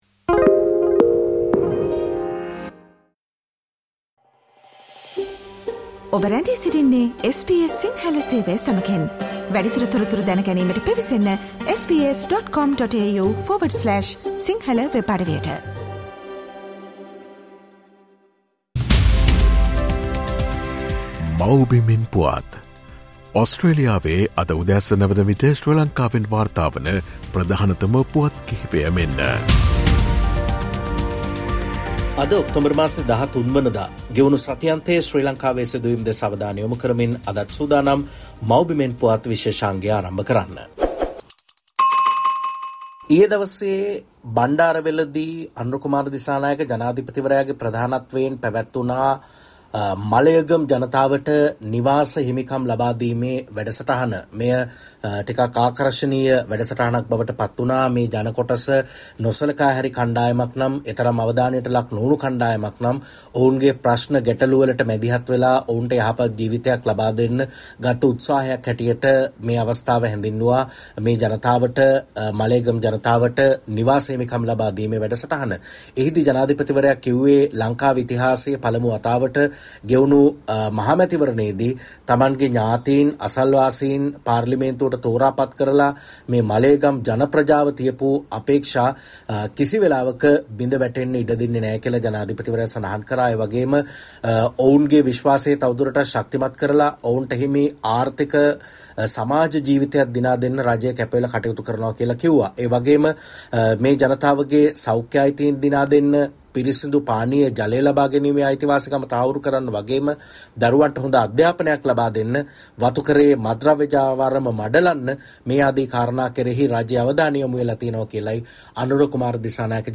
ගෙවුණු සතිඅන්තයේ ශ්‍රී ලංකාවෙන් වාර්තා වූ උණුසුම් හා වැදගත් පුවත් සම්පිණ්ඩනය.